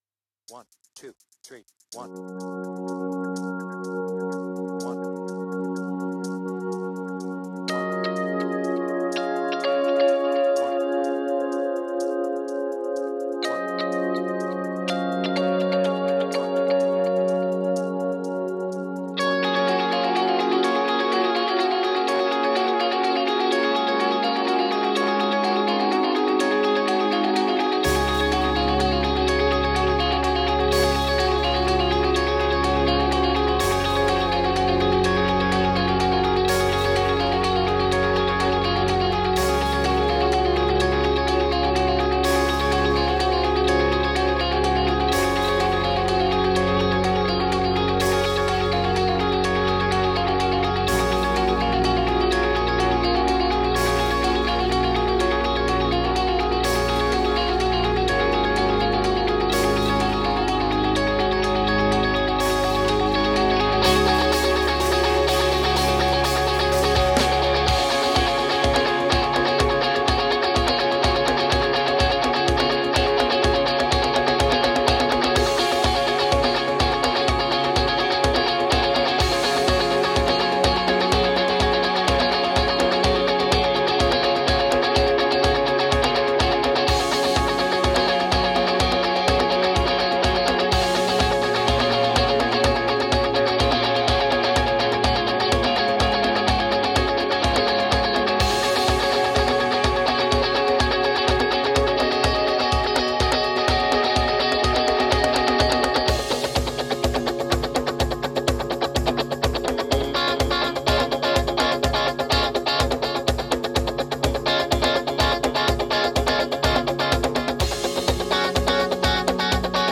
BPM : 125
Tuning : Eb
Without vocals